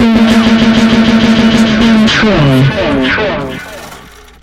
Been Trill (Distorted)(1).wav